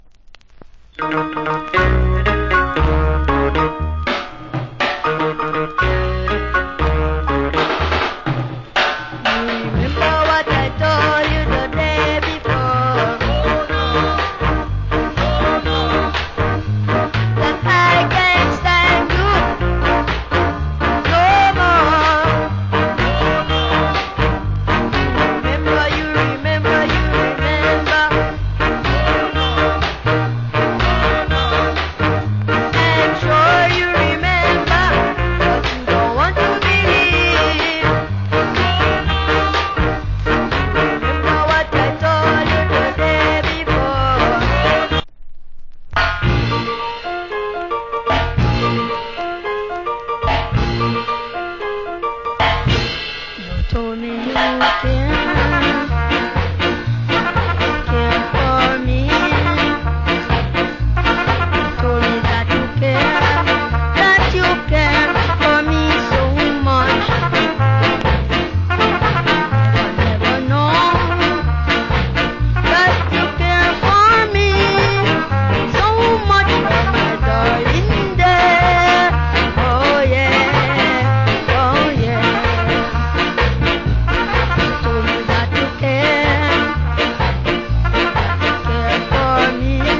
Rare Ska.